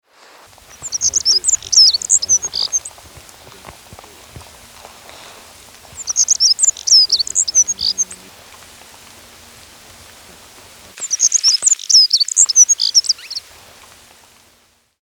alpine accentor
XC19592-Black-throated-Accentor-Prunella-atrog.mp3